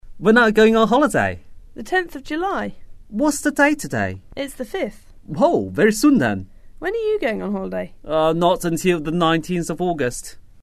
英语初学者口语对话第78集：你什么时候去度假?
english_9_dialogue_2.mp3